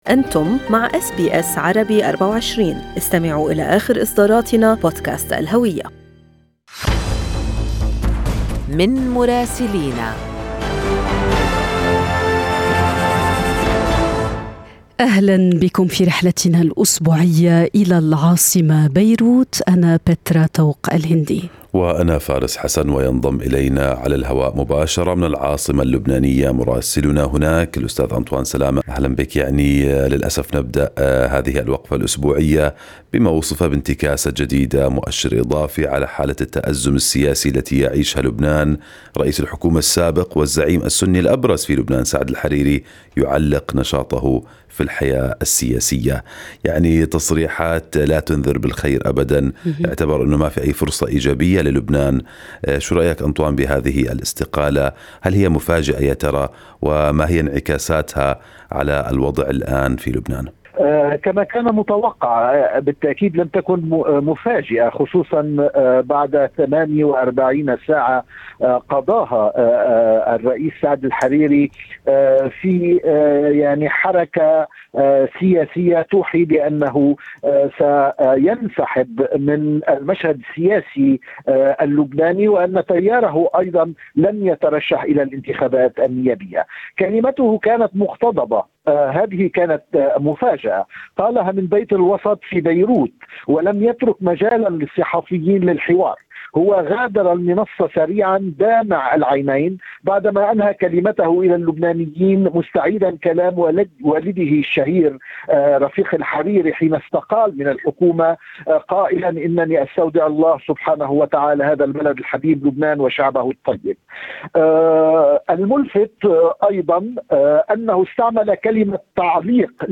أهم أخبار الدول العربية مع مراسلينا من لبنان ومصر والأراضي الفلسطينية والعراق والولايات المتحدة.
يمكنكم الاستماع إلى تقرير مراسلنا في لبنان بالضغط على التسجيل الصوتي أعلاه.